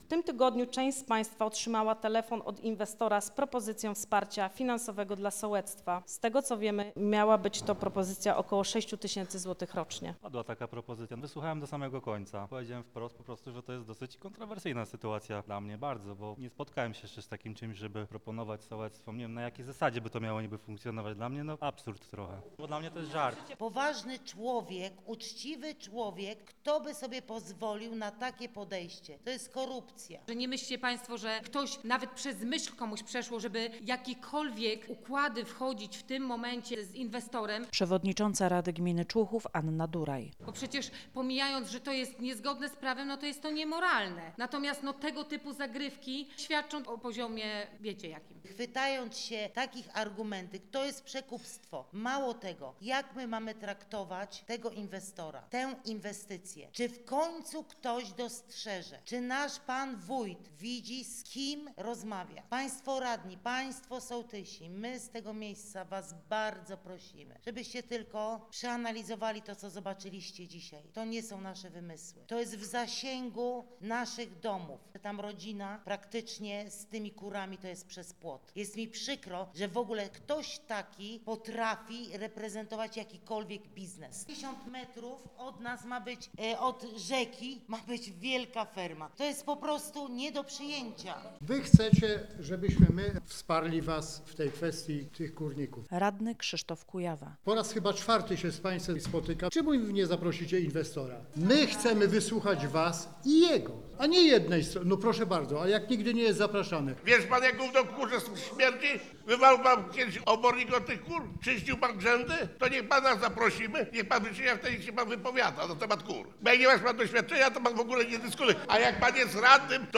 Posłuchaj materiału naszej reporterki: https